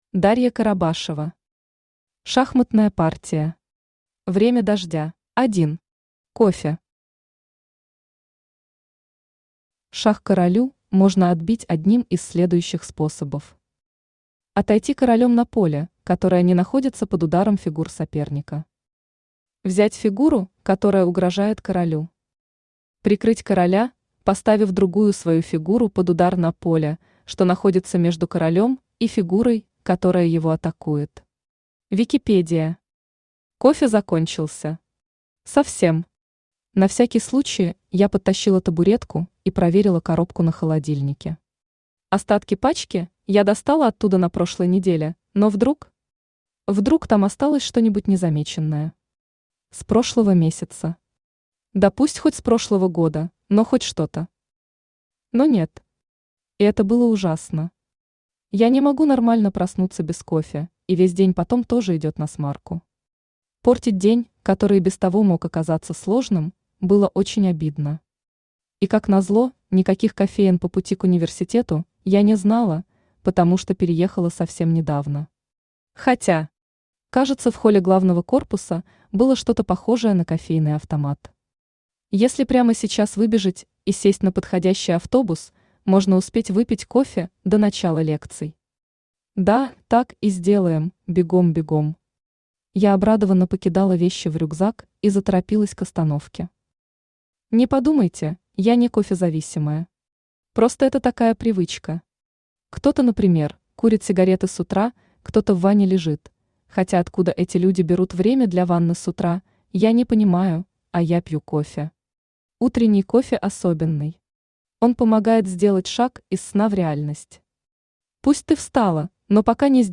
Аудиокнига Шахматная партия. Время дождя | Библиотека аудиокниг